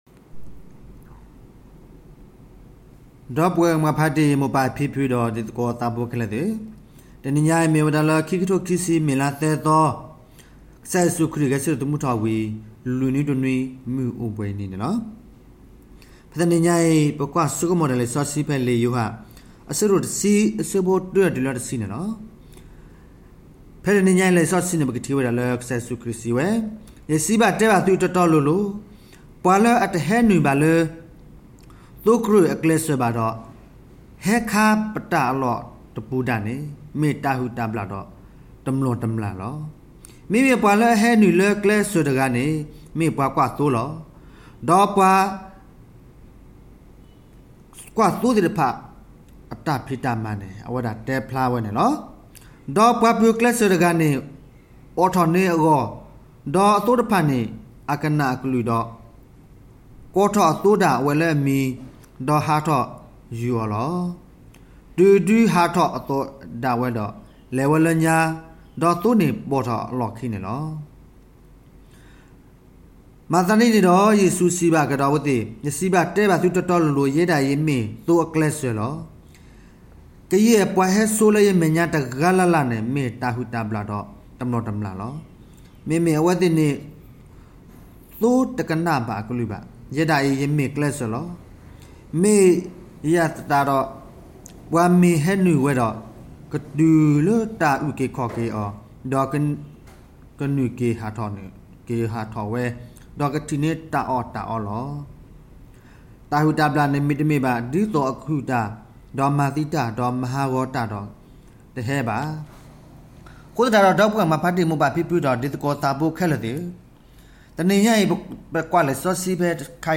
may_3_homily_2020.mp3